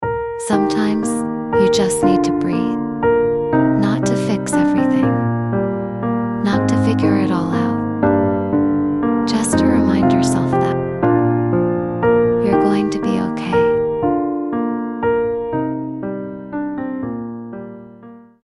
A calm and aesthetic video sound effects free download